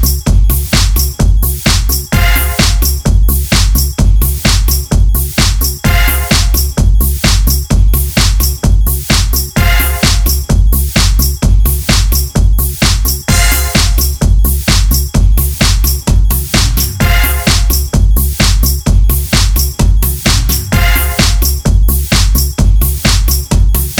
For Solo Rapper R'n'B / Hip Hop 4:09 Buy £1.50